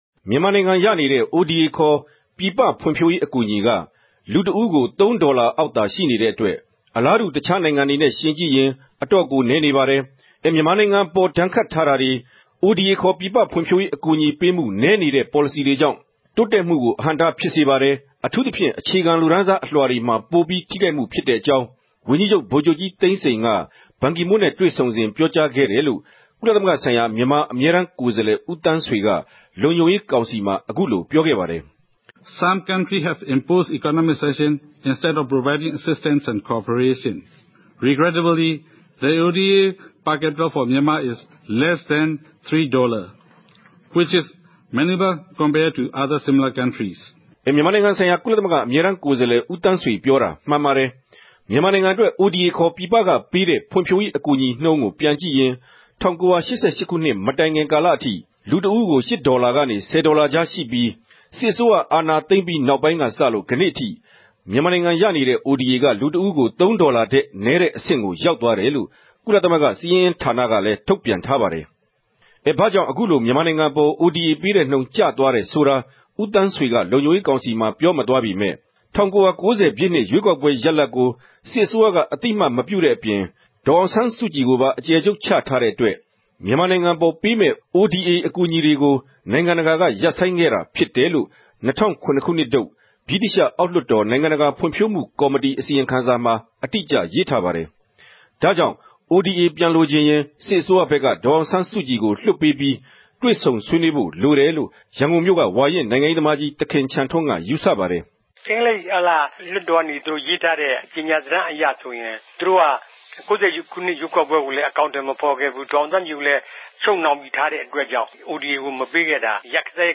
သုံးသပ်ခဵက်။